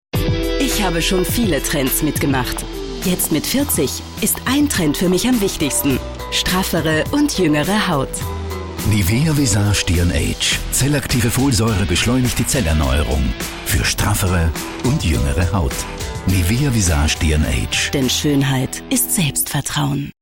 stimmprobe werbesprecherin . sprecher werbung . werbespots . tv spots . radiospots
Nivea Visage DNAge/TV-spot AT/mp3